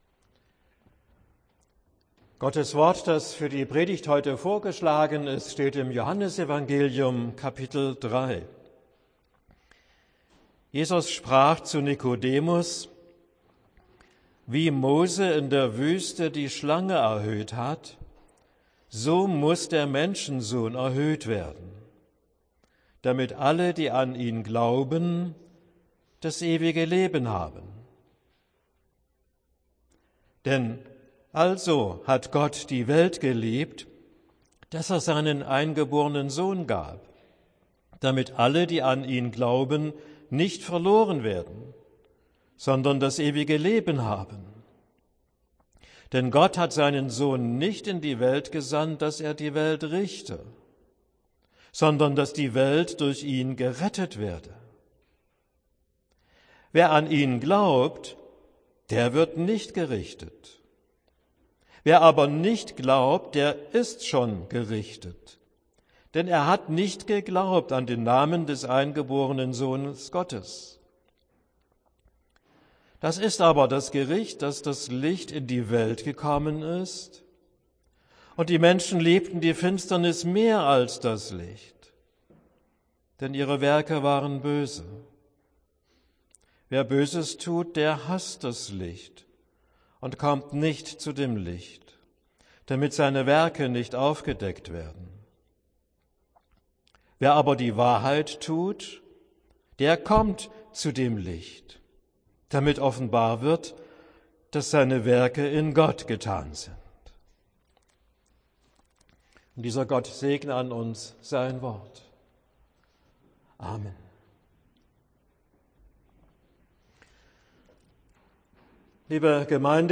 Predigt für den Sonntag Reminiszere